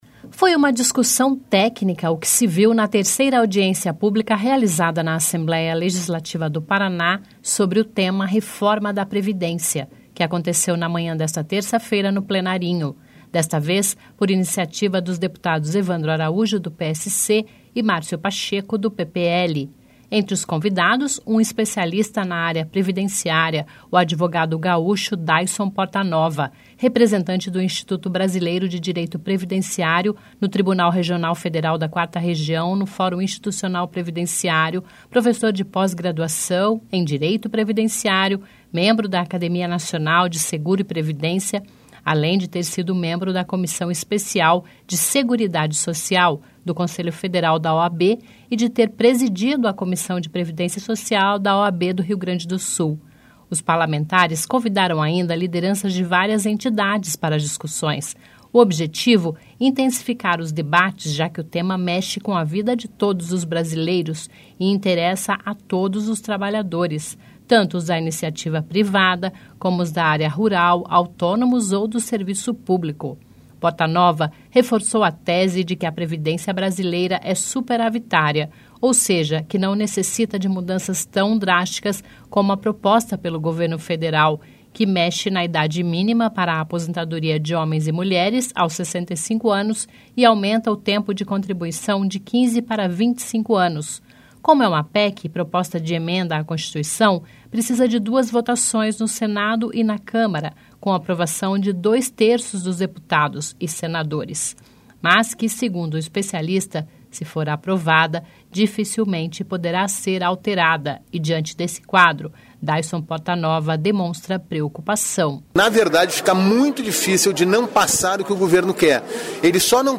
Reforma da Previdência volta a ser debatida em audiência pública na Alep